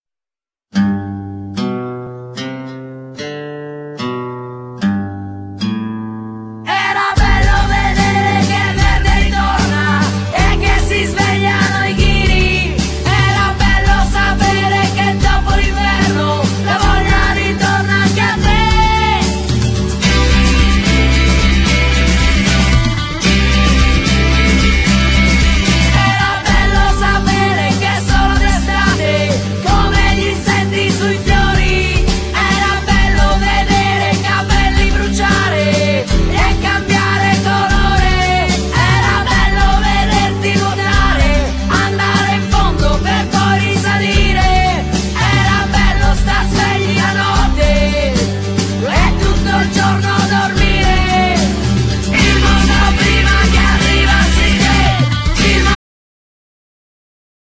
Genere : Pop / rock
Genere : Pop